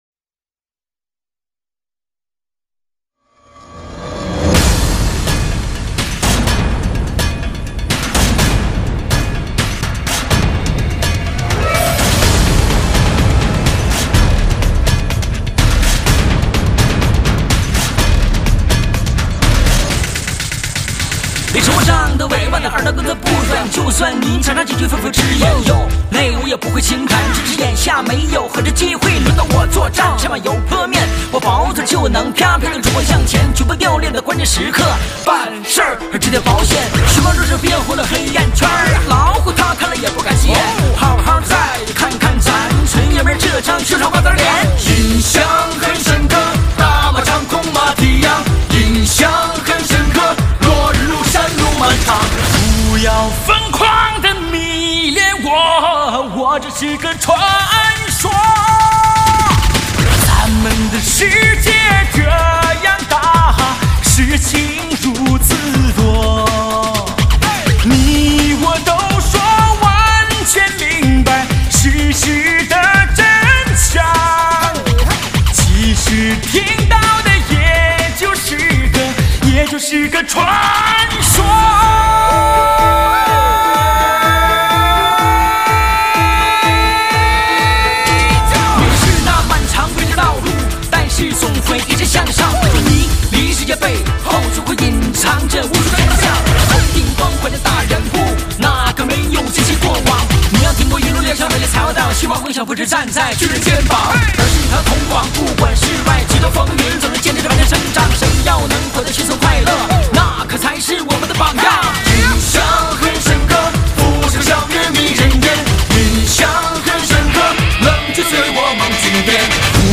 至尊汽车高清专用CD，美国版黑胶，完美音色，极品升华。